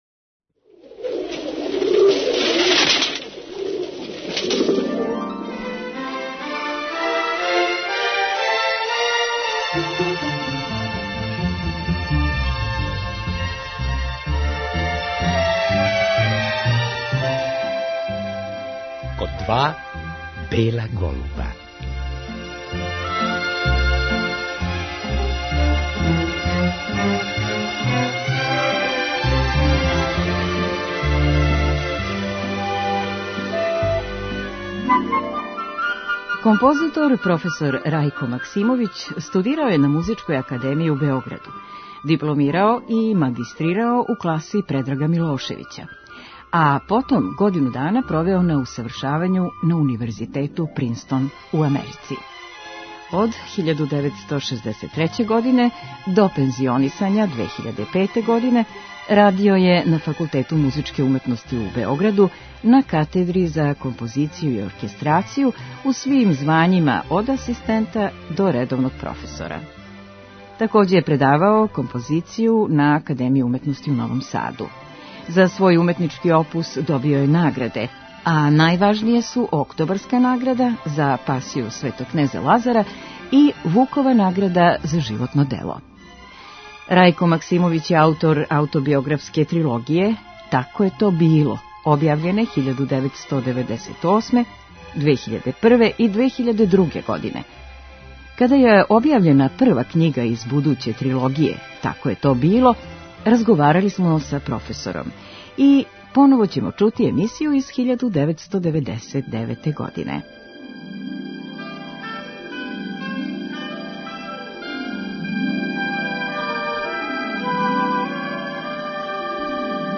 Када је објављена прва књига из будуће трилогије Тако је то било разговарали смо са професором и ми ћемо поново чути емисију из 1999. године.